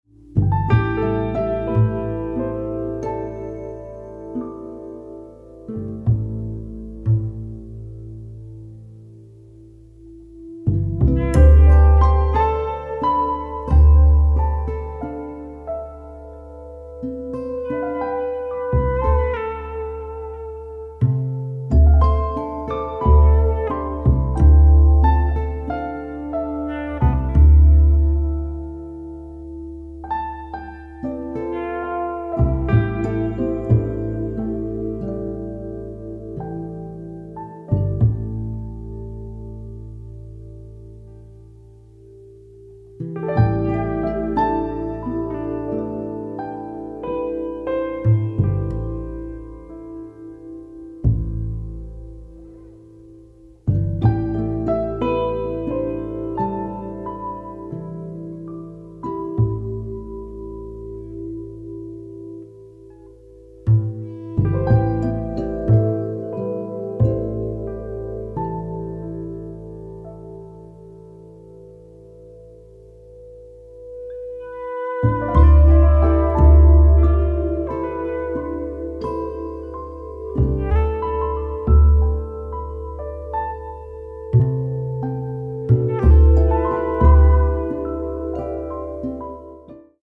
Out there electronix.
Electronix Indie Ambient